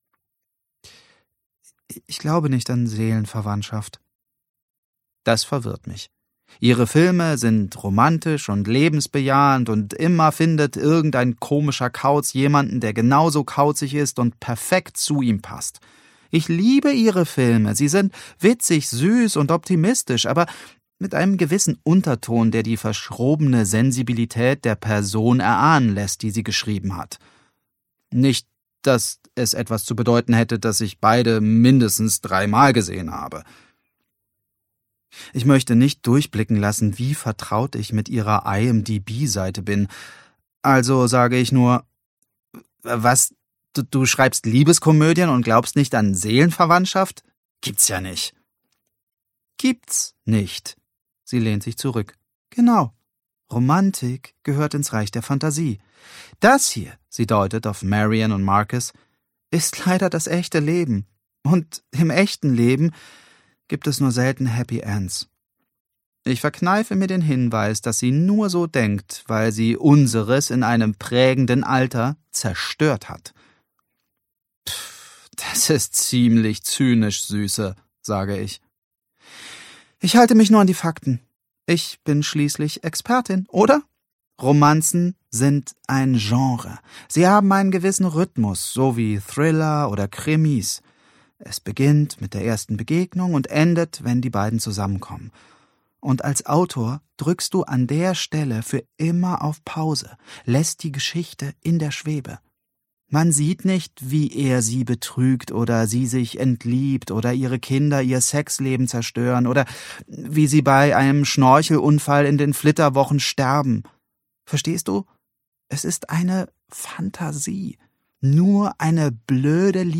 Ausgabe: Ungekürzte Lesung, Hörbuch Download